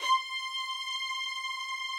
strings_072.wav